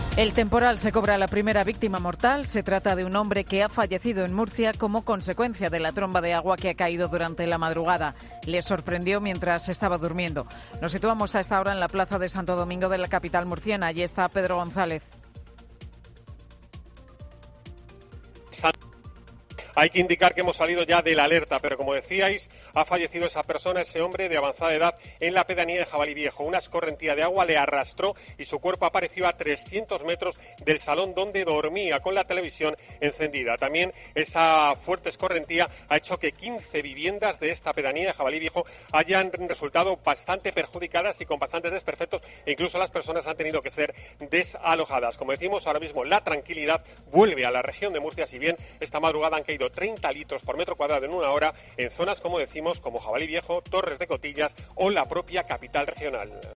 Fallece un hombre, cuya casa fue arrasada por la tromba de agua en Murcia. Crónica